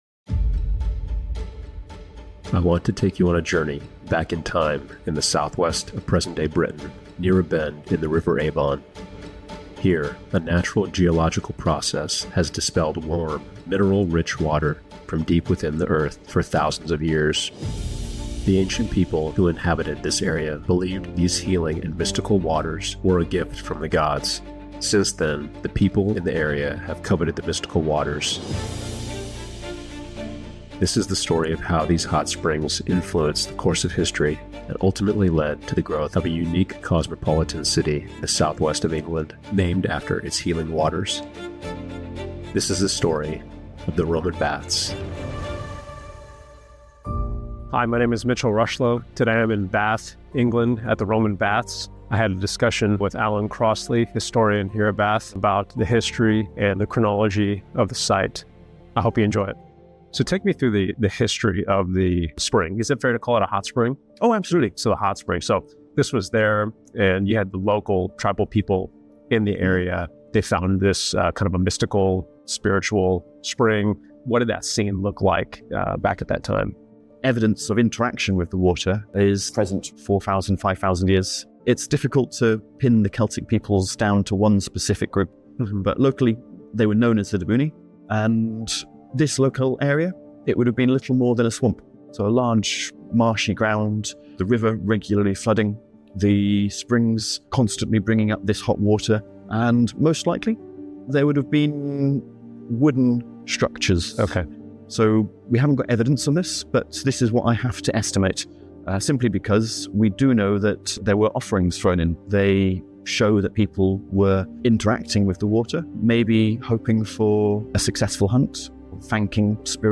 High-quality on-site footage and detailed expert interviews reveal captivating insights into this ancient site.